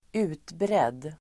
Uttal: [²'u:tbred:]